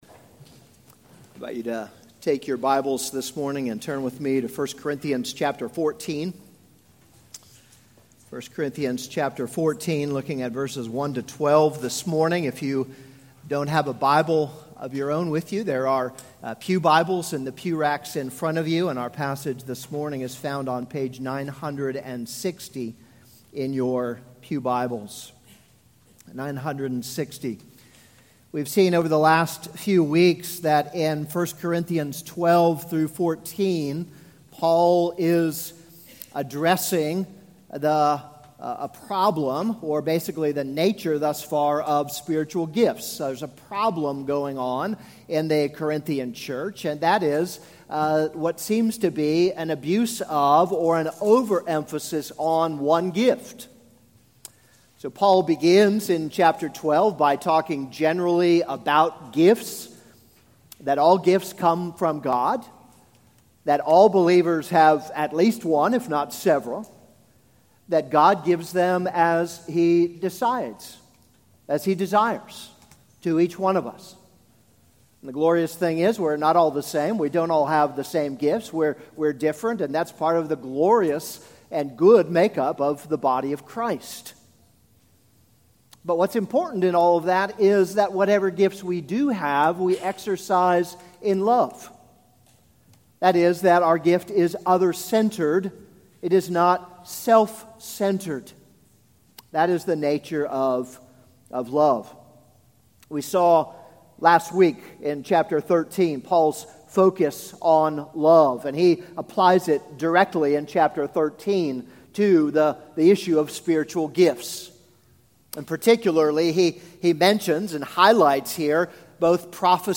This is a sermon on 1 Corinthians 14:1-12.